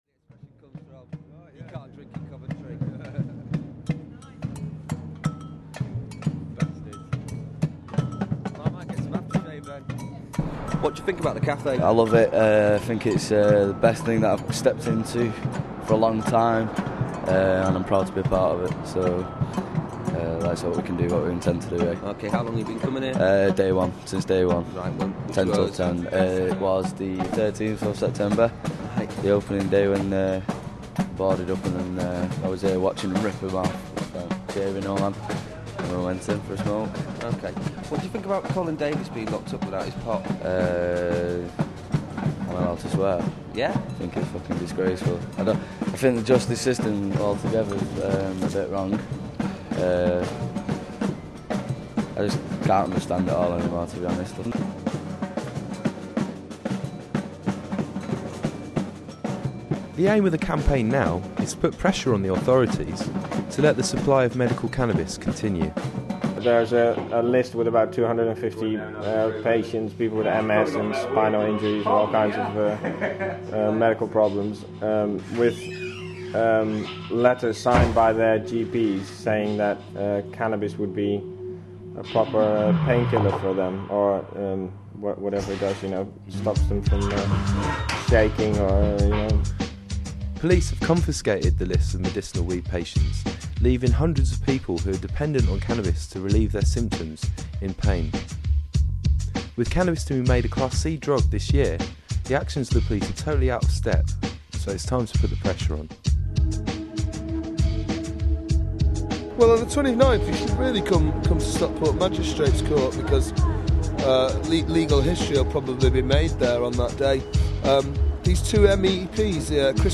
A posse from manchester got together a samba experience to Get the people of Stockport aware of C-Day - in support of medical cannabis - jan 29th (article 1)